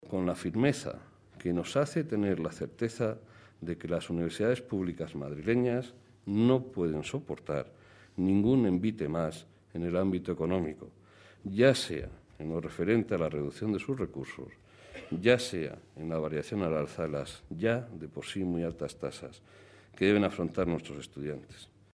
El rector de la Universidad Politécnica de Madrid (UPM), Carlos Conde, ha dicho hoy, en el acto de apertura oficial del curso académico 2013-2014, que las seis universidades públicas de la región "no pueden soportar ningún envite más", ni de recorte de recursos ni de subida de las tasas.
En su discurso, el rector de la Politécnica ha afirmado que "el curso se presenta cargado de incertidumbre y desasosiego" y que la universidad está "en una situación límite y con más necesidad que nunca del apoyo y la comprensión de las máximas autoridades nacionales y madrileñas".